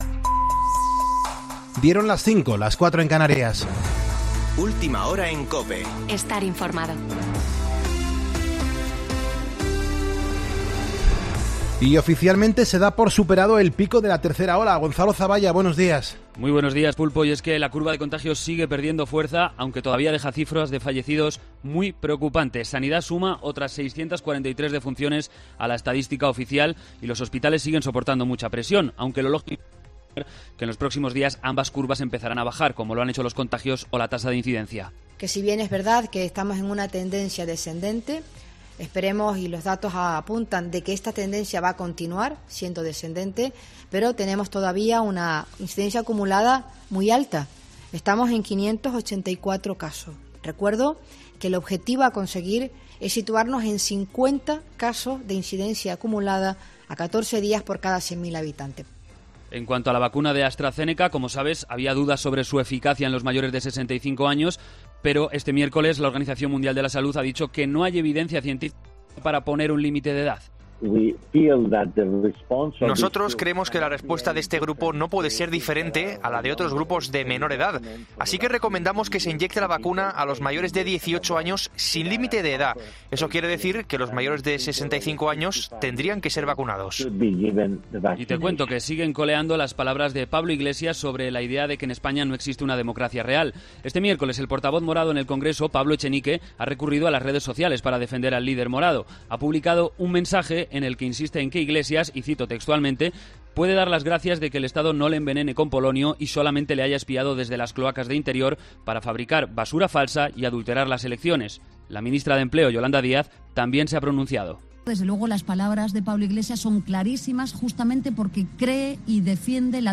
Boletín de noticias COPE del 11 de febrero de 2021 a las 05.00 horas